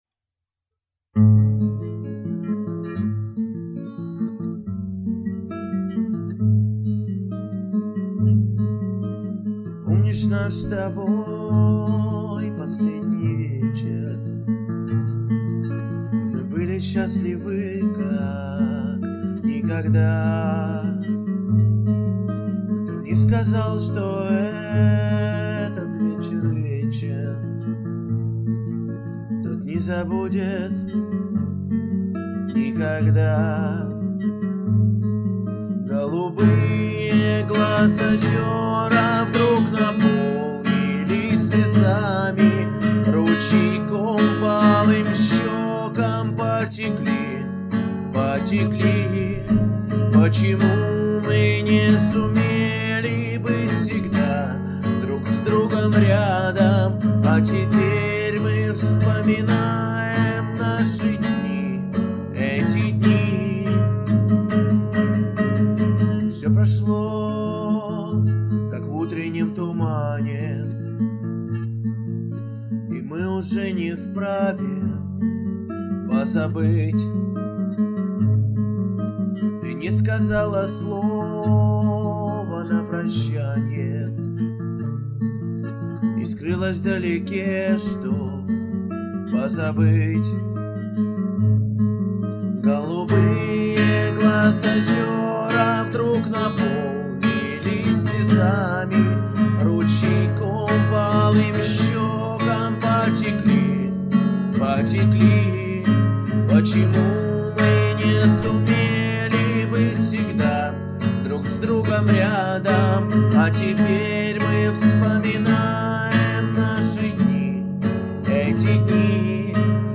pod-gitaru-Golubie-glaz-ozera-stih-club-ru.mp3